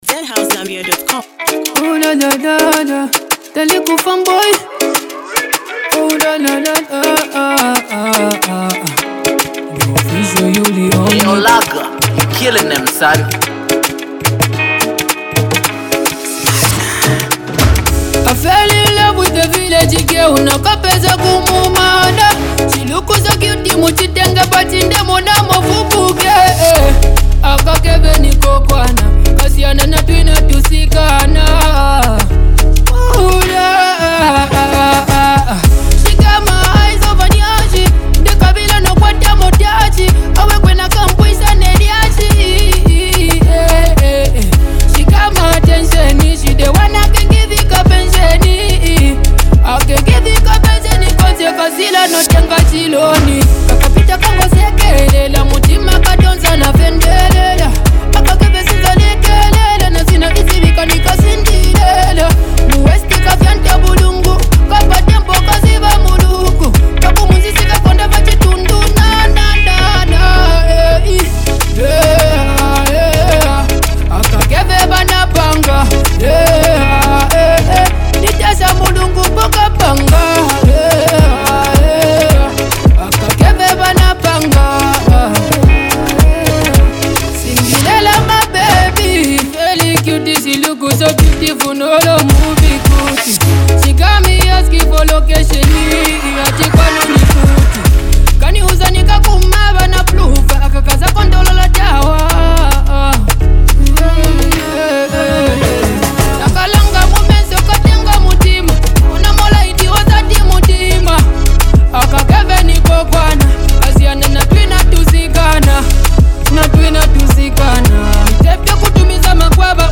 heartfelt vocals and storytelling